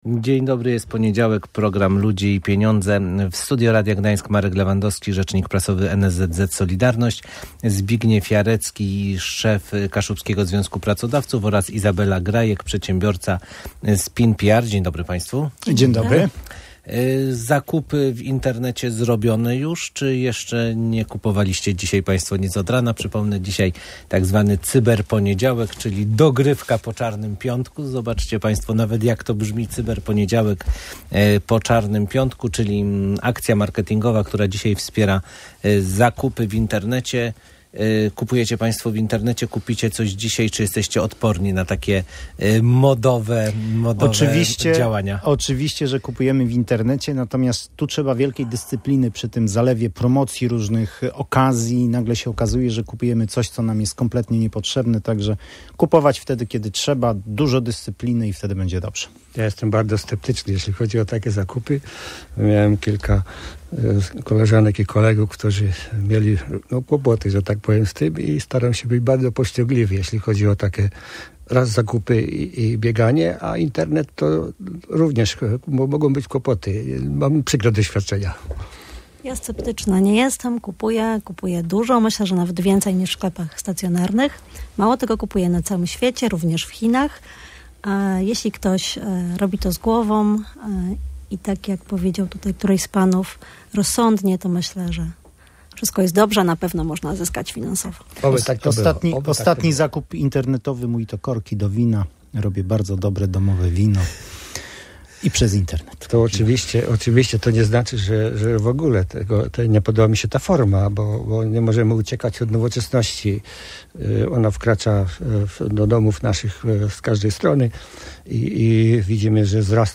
Eksperci goszczący w Radiu Gdańsk podkreślają jednak, że należy zachować ostrożność i nie dać się zwieść zakupowej gorączce.